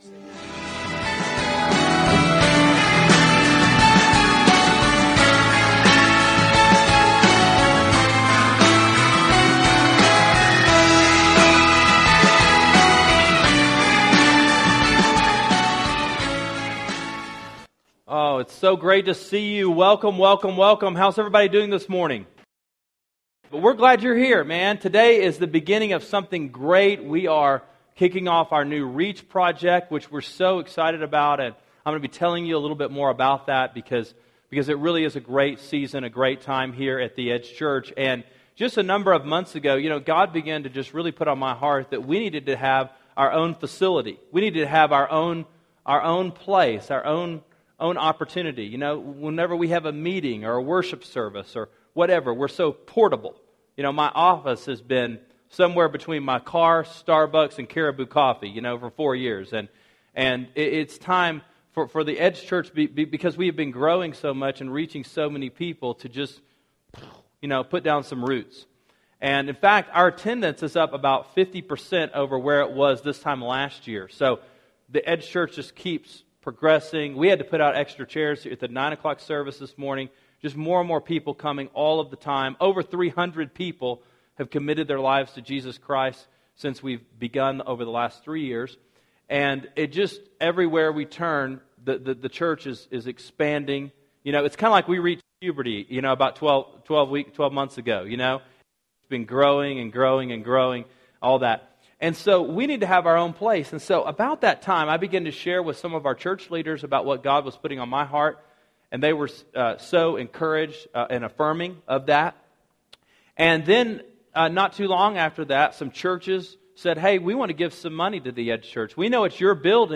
Reach: Make A Move – Genesis 12:1-9 – Sermon Sidekick